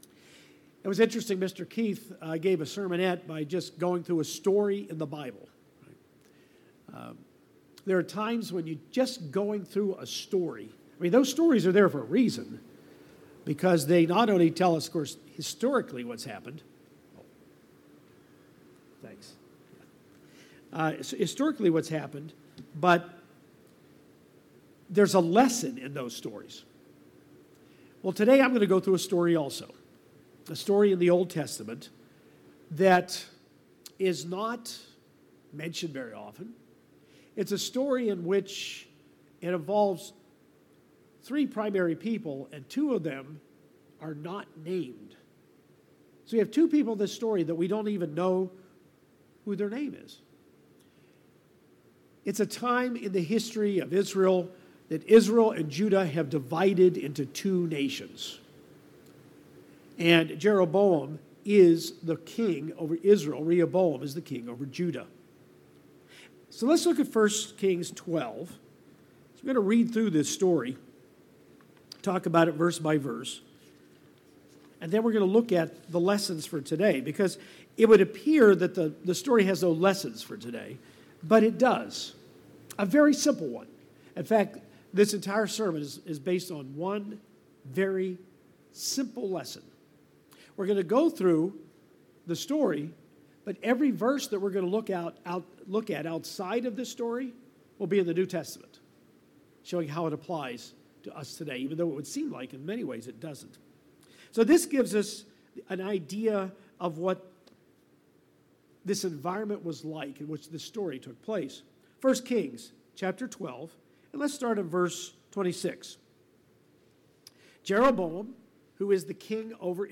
In fact, this entire sermon is based on one very simple lesson.